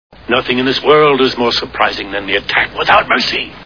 Little Big Man Movie Sound Bites
Richard Mulligan as General George Armstrong Custer: "Nothing in this world is more surprising than the attack without mercy!"